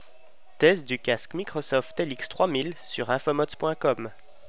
Pour ce qui est de l'atténuateur de bruit, ce n'est pas un gadget et cela fonctionne vraiment, comme vous pourrez en juger avec les extraits sonores suivant (bruit ambiant assez conséquent)... qui ont, qui plus est, été enregistrés alors que j'avais une grippe, avec le nez bouché et tout le reste... Donc pas vraiment les conditions optimales pour un test de micro, et pourtant le tout est entièrement compréhensible.
lx3000-musiqueambiante.wav